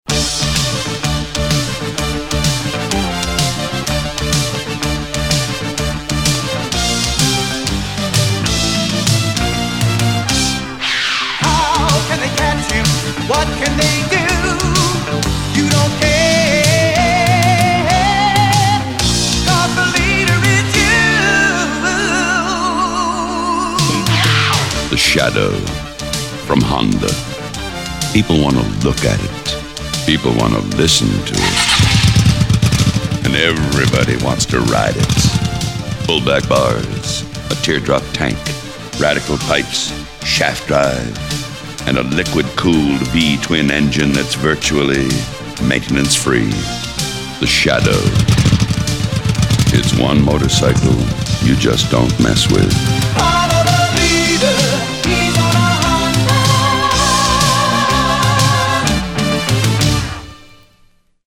The broadcast was sponsored by Honda and Honda was trying to sell the Shadow. Every commercial break had the same commercial contained within.
To Honda's credit, I think the sound of the bike in the commercial is an actual Shadow.